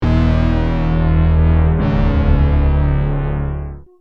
Free MP3 vintage Sequential circuits Pro-600 loops & sound effects 2
Sequencial Circuits - Prophet 600 43